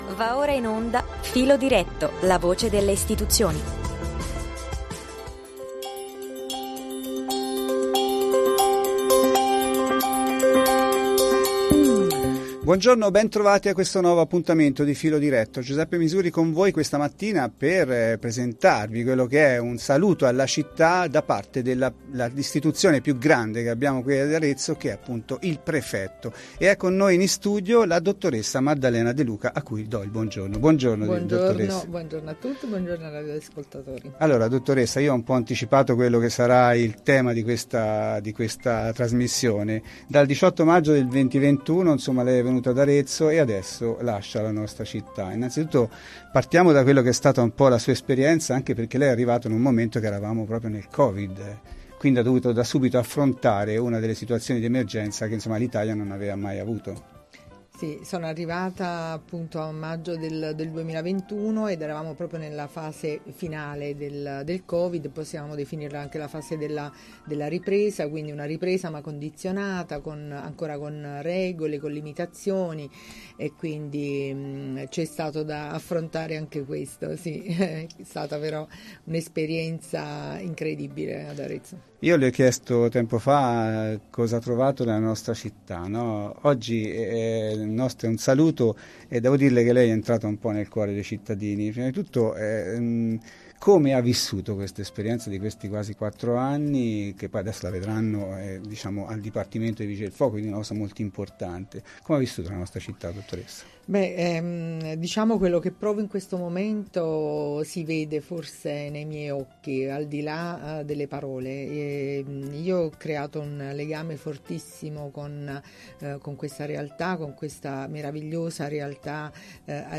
In studio quindi i suoi più sinceri e affettuosi saluti, alla città di Arezzo.